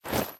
Minecraft Version Minecraft Version snapshot Latest Release | Latest Snapshot snapshot / assets / minecraft / sounds / item / armor / equip_chain6.ogg Compare With Compare With Latest Release | Latest Snapshot
equip_chain6.ogg